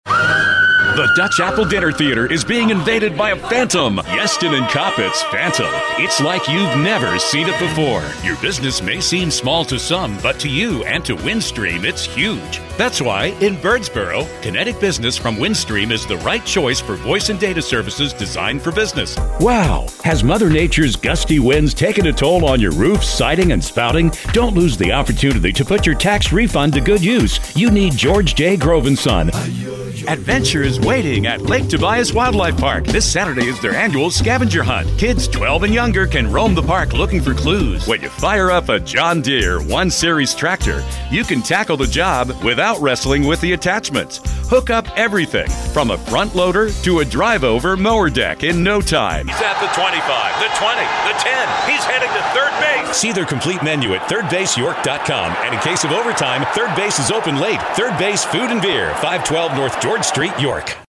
商业广告